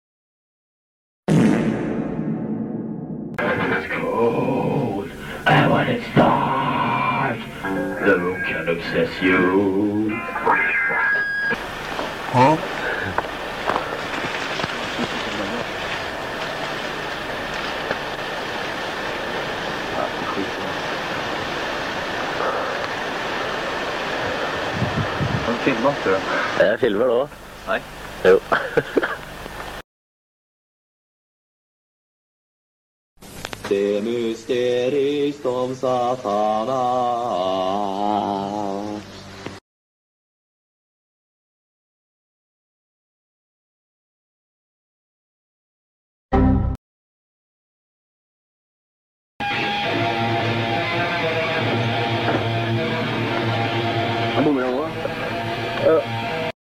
section of the interview is sound effects free download